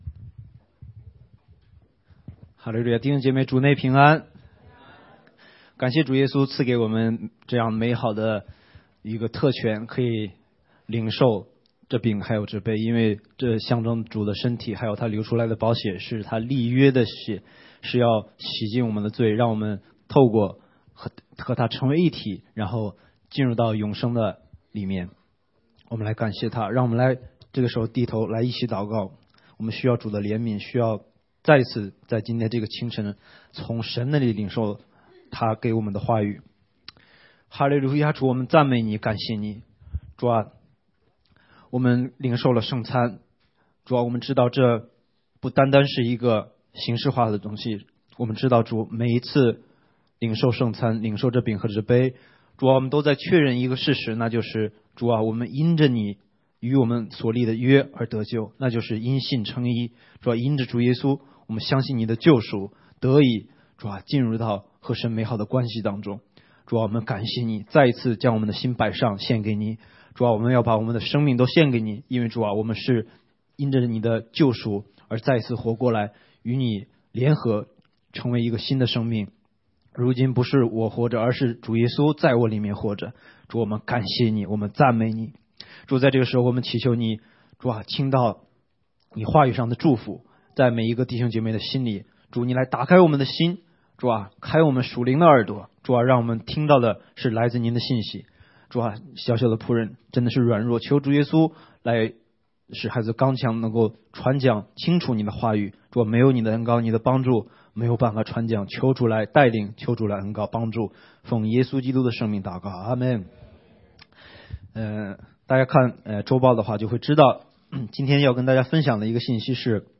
下午堂《连驴子都开口说话了》